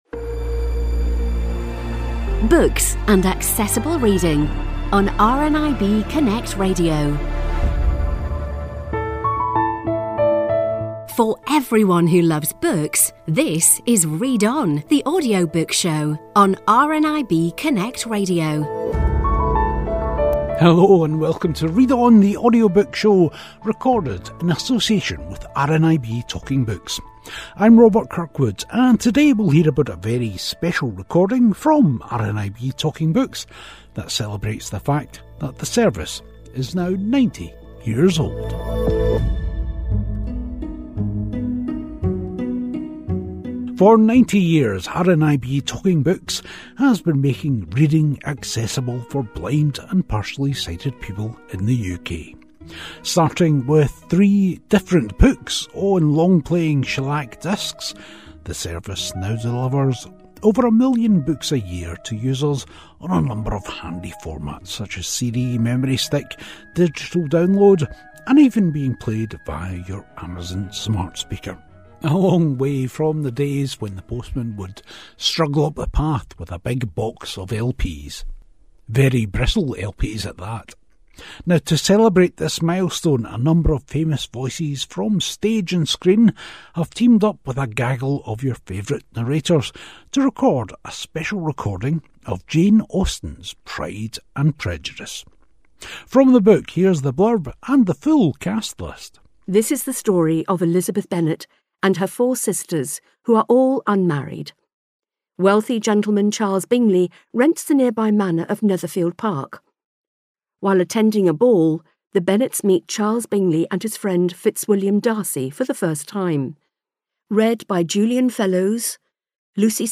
Ben Willbond, Phil Dunster, Lisa George and Shazia Mirza talk about narrating Jane Austen and give us some great book recommendations.